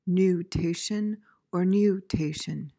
PRONUNCIATION:
(noo/nyoo-TAY-shuhn)